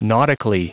Prononciation du mot : nautically
nautically.wav